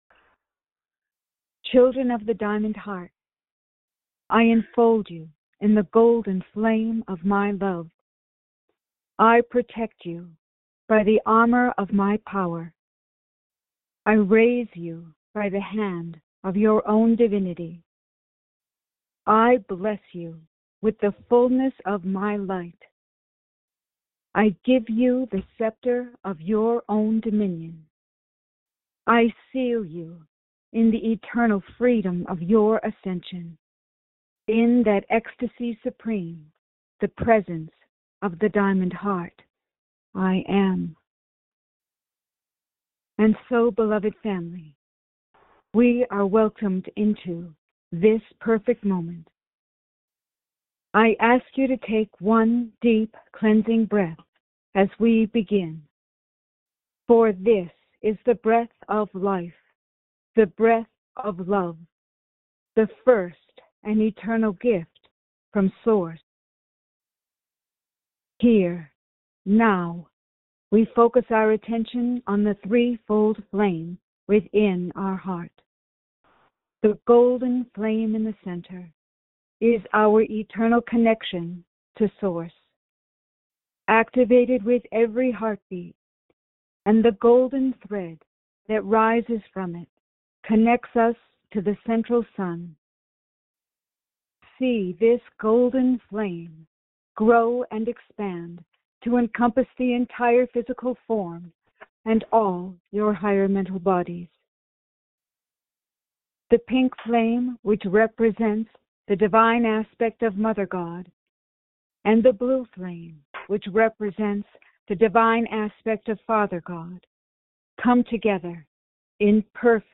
Join in group meditation with Lord Sananda (Jesus).